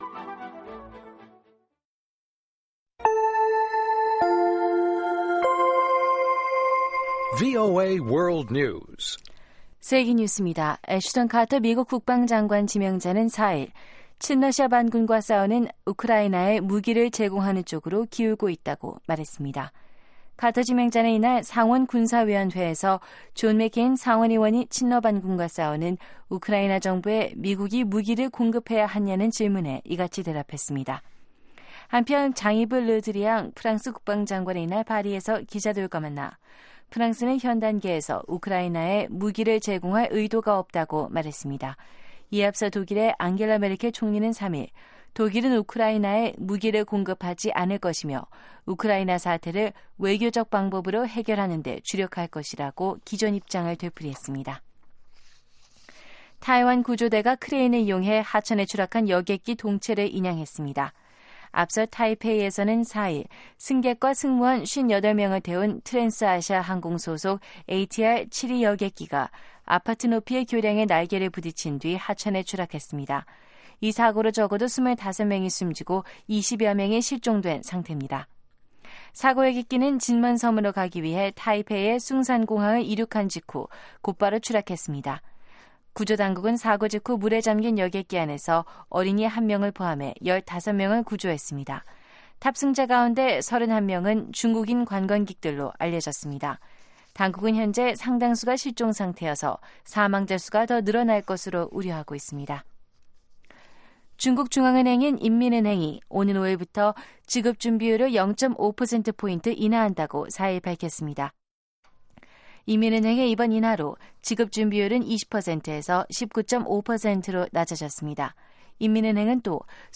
VOA 한국어 방송의 시사 교양 프로그램입니다.